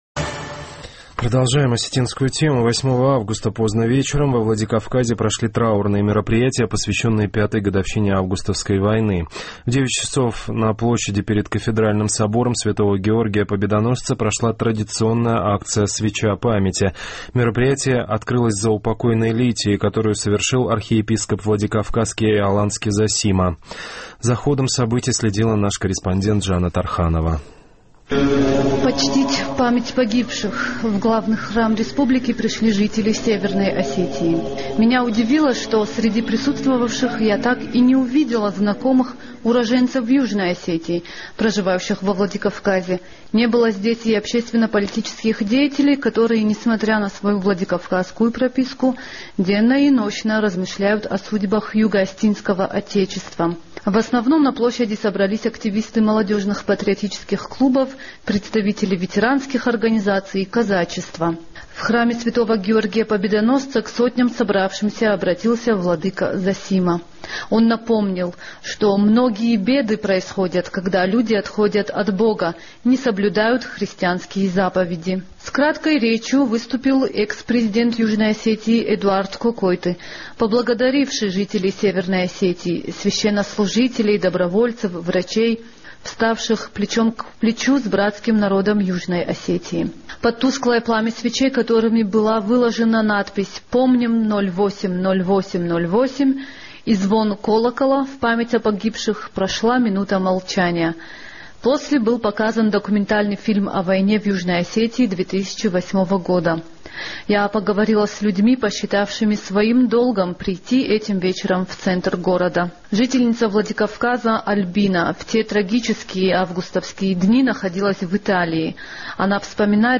Я поговорила с людьми, посчитавшими своим долгом прийти этим вечером в центр города.